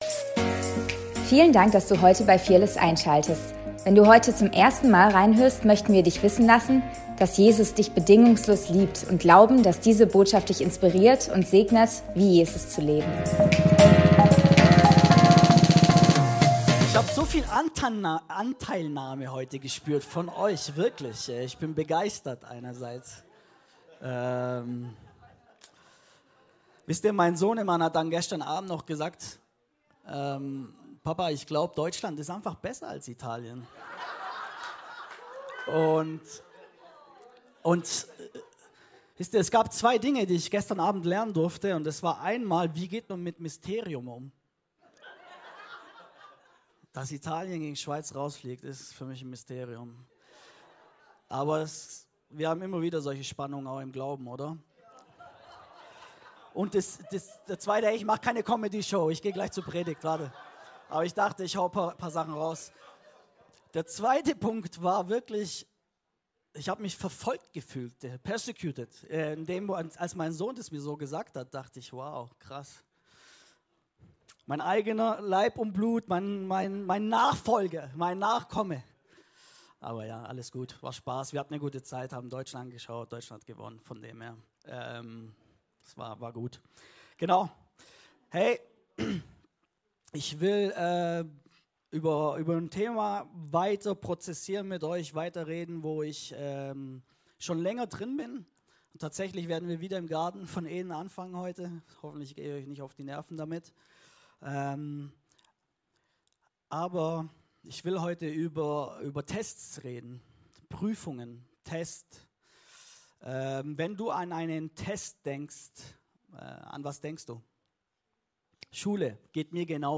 Predigt vom 30.06.2024